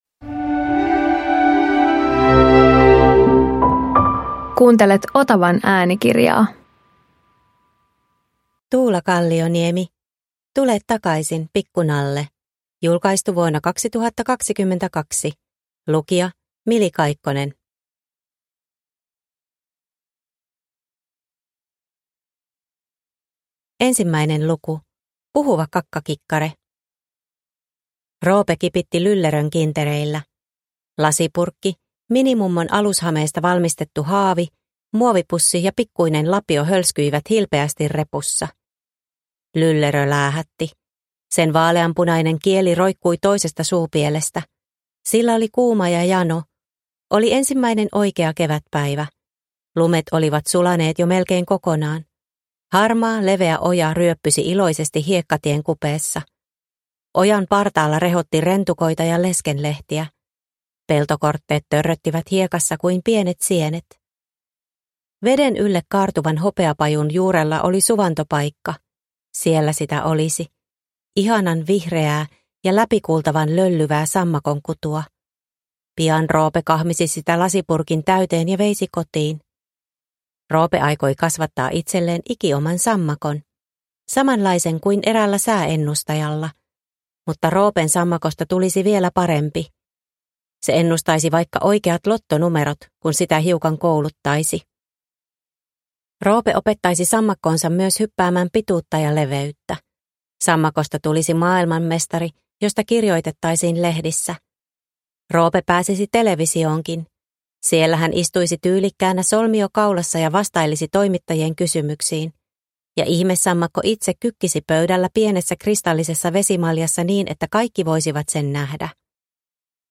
Tule takaisin, pikku nalle – Ljudbok